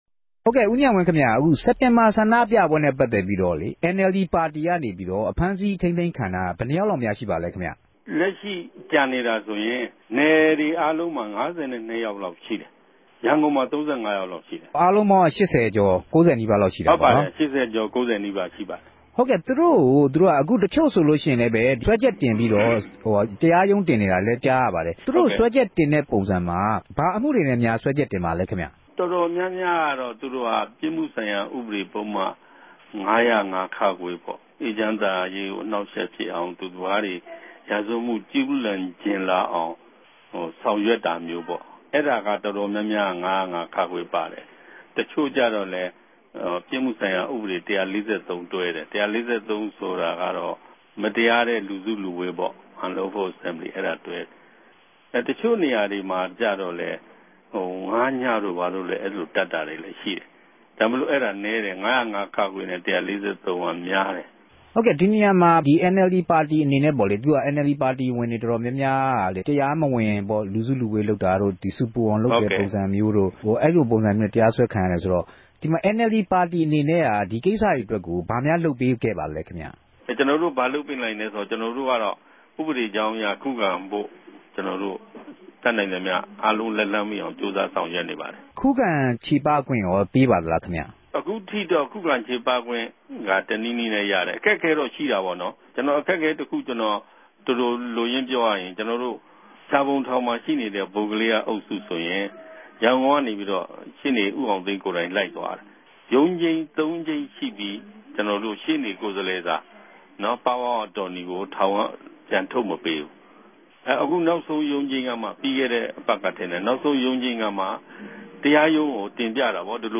ဆက်္ဘပီးမေးူမန်းထားတာကို နားဆငိံိုင်ပၝတယ်။